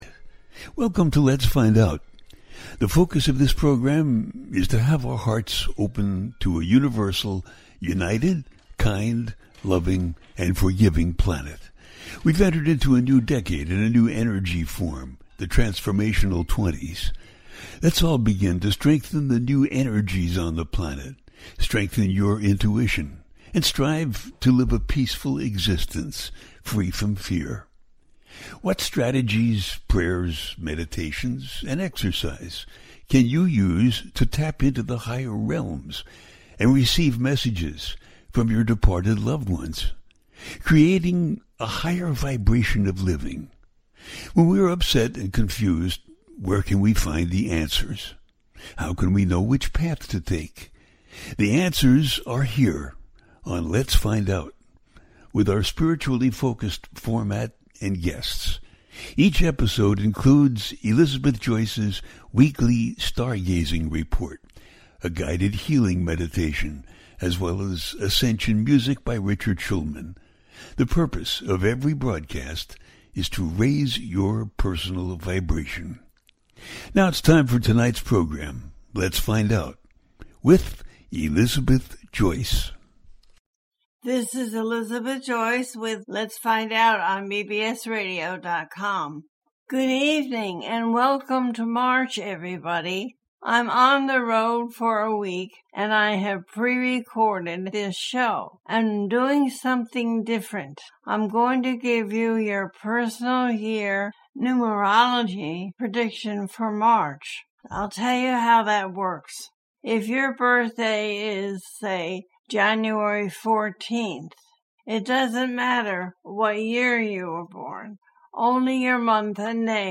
Numerology For March 2025 and 2025 Predictions - A teaching show